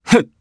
Ezekiel-Vox_Jump_jp_b.wav